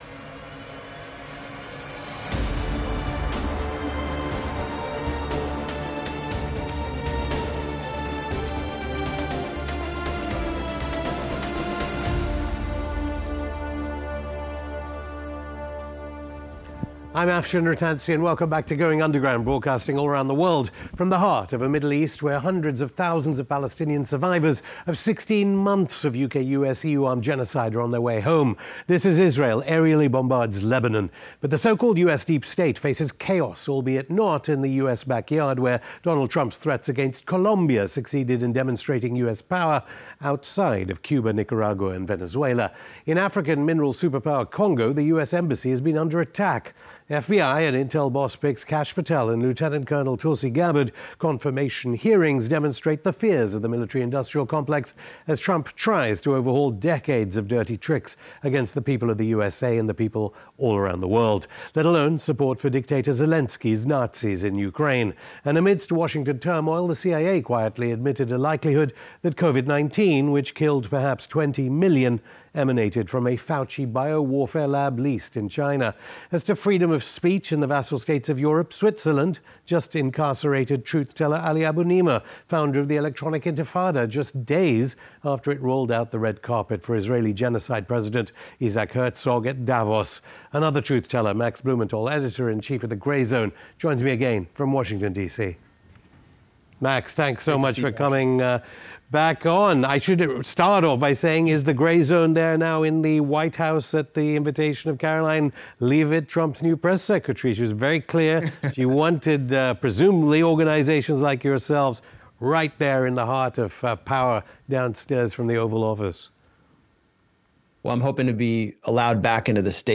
Max Blumenthal on US’ Ukraine Aid Corruption, 'Psychotic' Israel Turning the West Bank into Gaza (Afshin Rattansi interviews Max Blumenthal; 03 Feb 2025) | Padverb